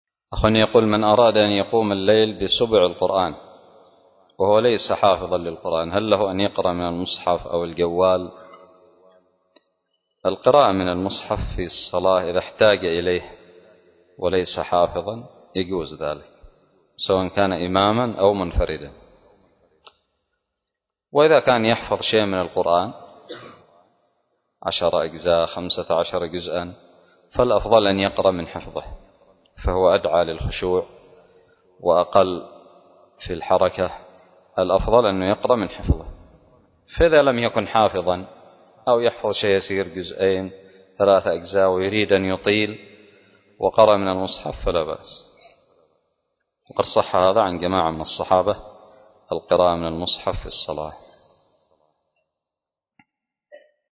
فتاوى الصلاة